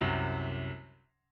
piano7_37.ogg